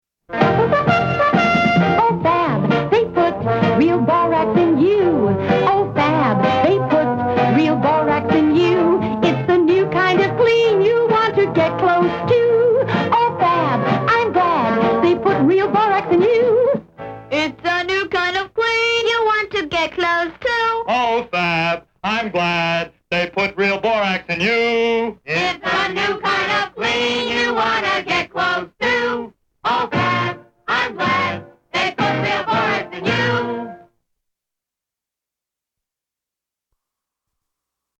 which sounds like it employed
the singers who auditioned,
but were rejected, for every
other jingle heard on the air.